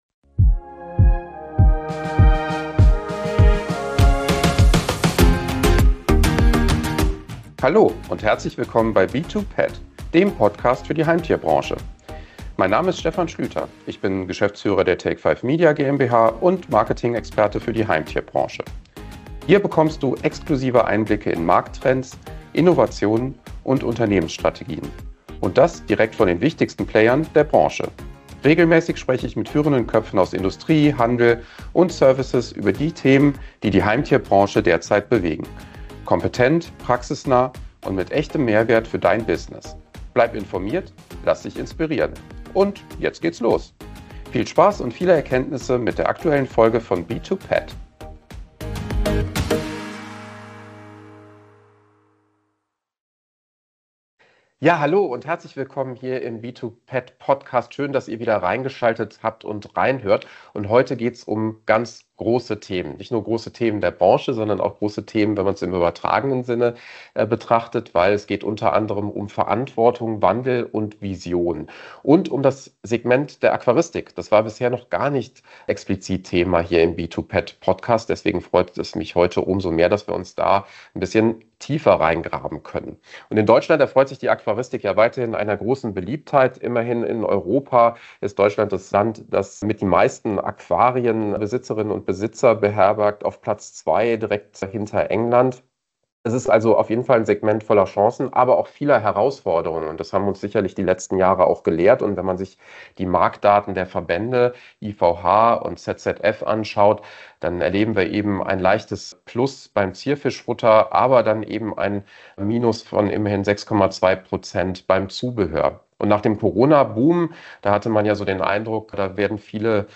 Ein persönliches Gespräch über Verantwortung, Werte, moderne Führung – und die Liebe zur Unterwasserwelt.